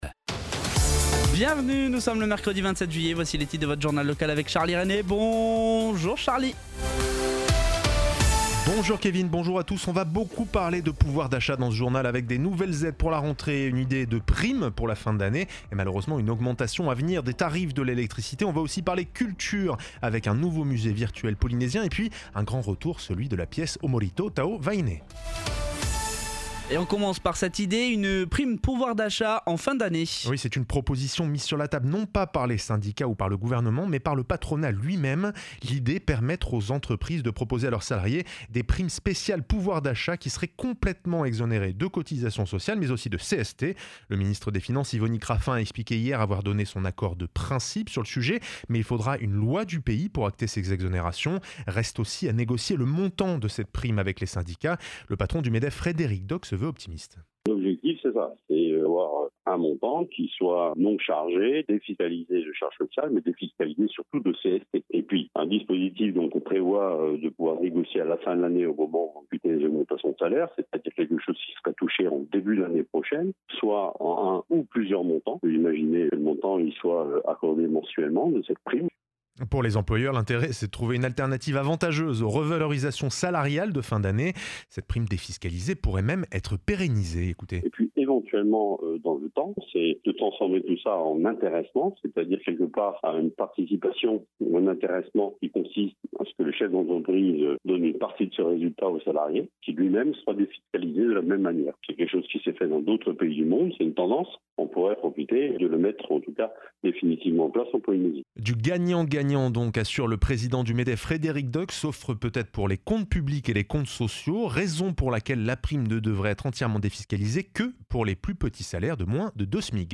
Journal de 7h30, le 27/07/22